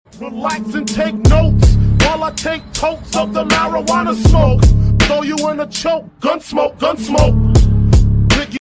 Golf 6 > Backup camera sound effects free download